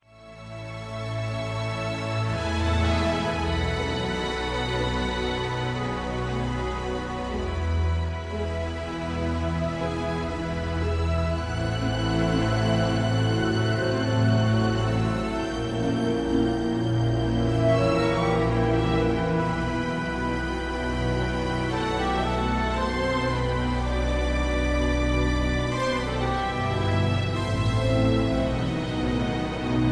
(Key-E) Karaoke MP3 Backing Tracks